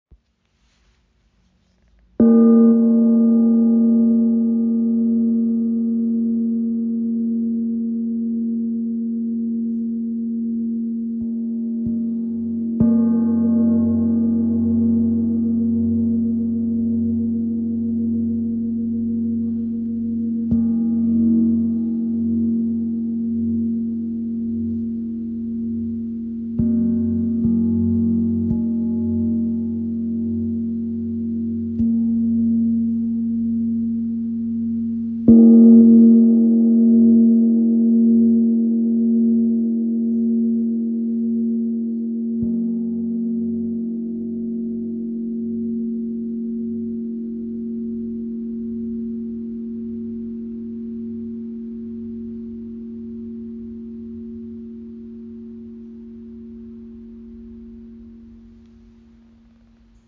Klangbeispiel
Der VOX Gong hat die Eigenschaft, besonders lebendig auf der mittel-hohen Frequenzen zu sein. Er reagiert besonders gut in der Grundfrequenz und hält dabei das volle tonale Zentrum bei.